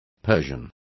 Complete with pronunciation of the translation of persians.